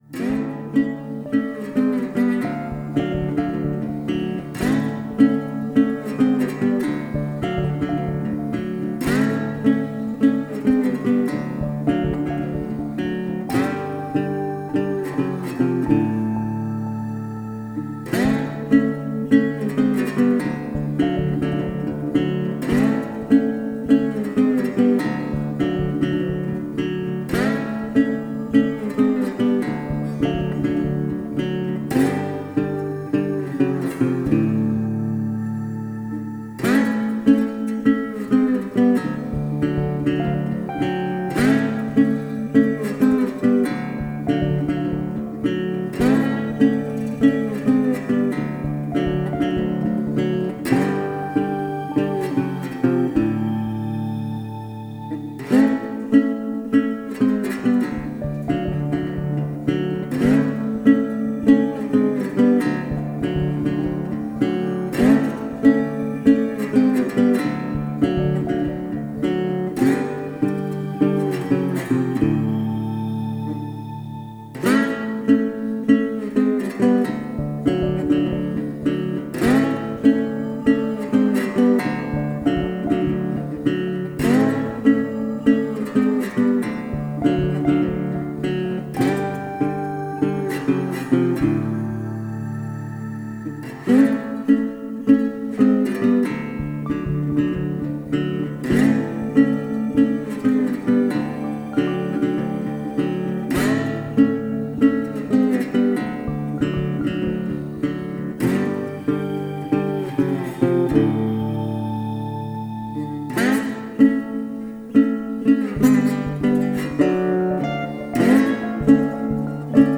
Delta Blues Intro. Bottleneck mit Dobro Gitarre.